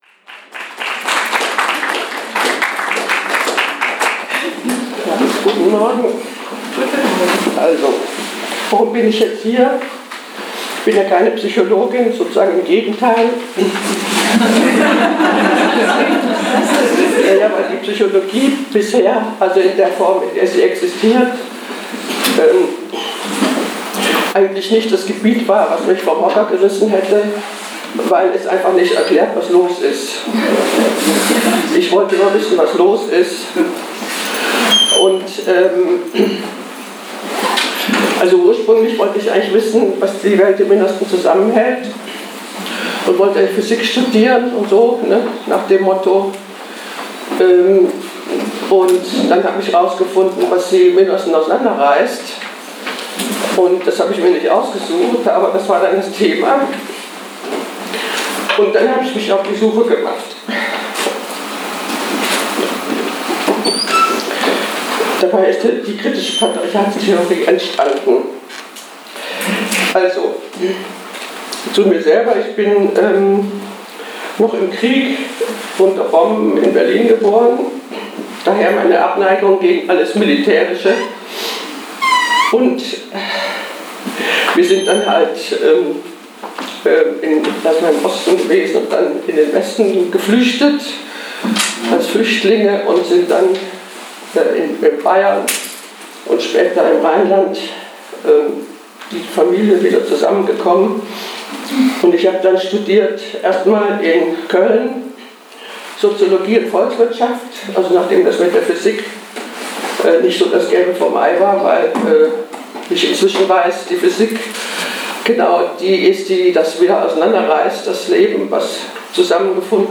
Live-Mitschnitt Vortrag